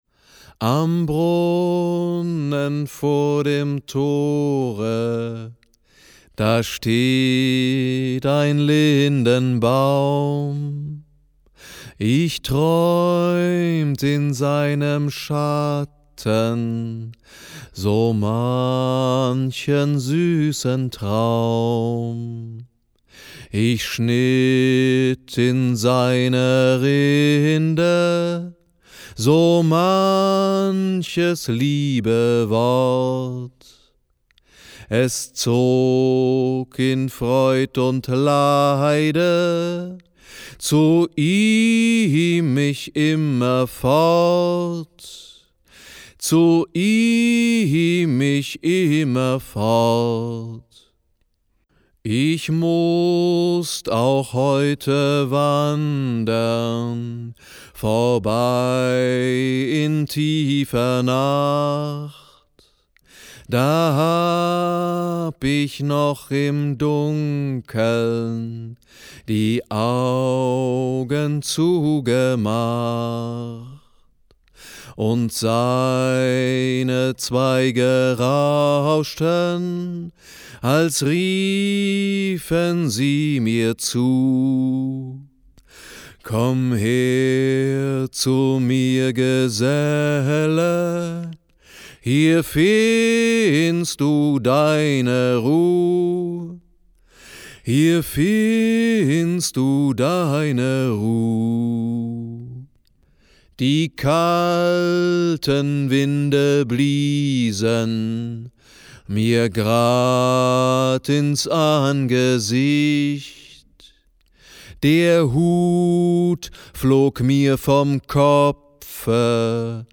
Kern der SingLiesel-Bücher bilden illustrierte (Volks-)Lieder, die abgespielt und mitgesungen werden können und durch haptische Fühl- und Klapp-Elemente ergänzt werden.
Die schönsten Heimatlieder: